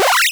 Heal.wav